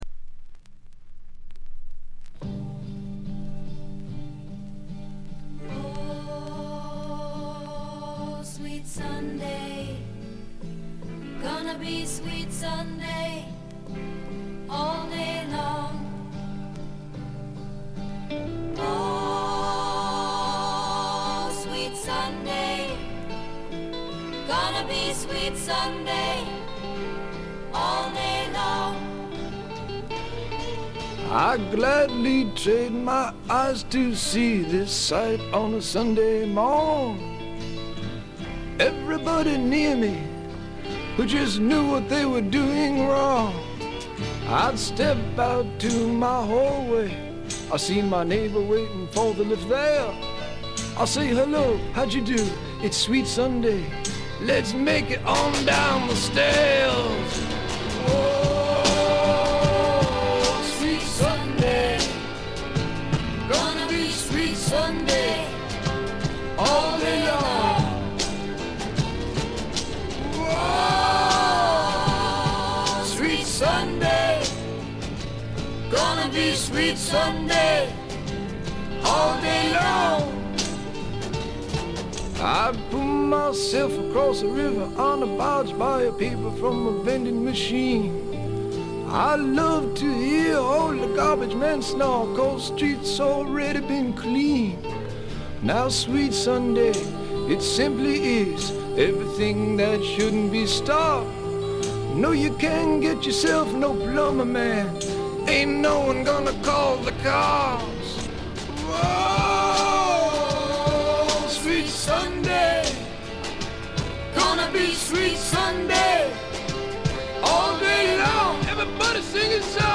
静音部（ほとんどないけど）でチリプチ少々、散発的なプツ音が2-3回出たかな？って程度。
試聴曲は現品からの取り込み音源です。
Backing Vocals